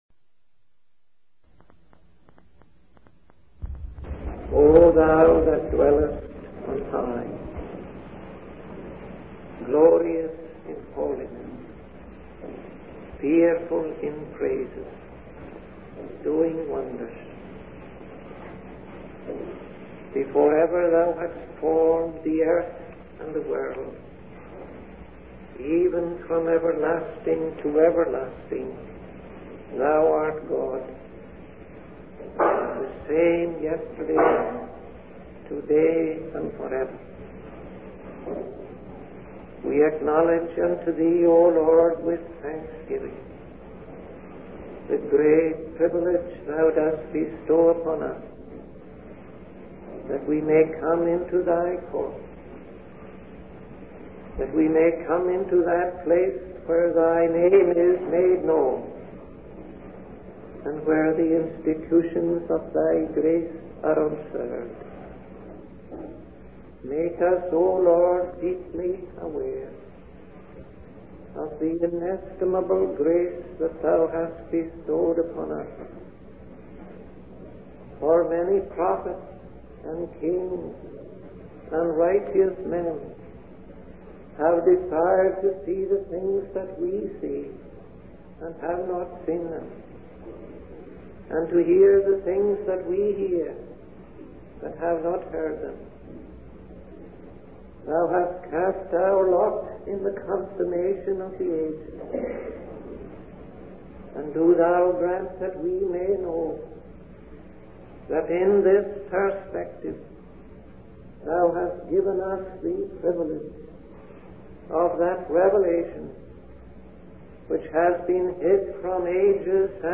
In this sermon, the preacher emphasizes the finality of death and the importance of not wasting the precious opportunities given to us.